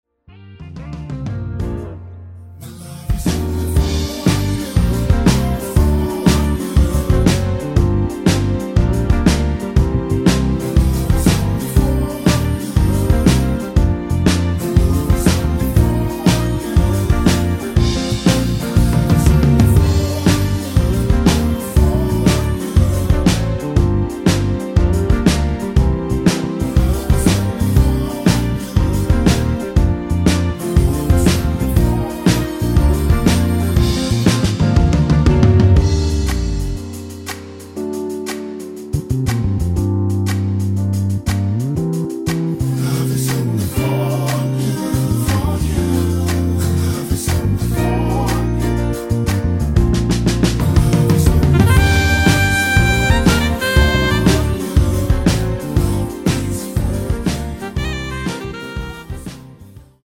코러스 포함된(-2) 내린MR 입니다.(미리듣기 참조)
Bb
◈ 곡명 옆 (-1)은 반음 내림, (+1)은 반음 올림 입니다.
앞부분30초, 뒷부분30초씩 편집해서 올려 드리고 있습니다.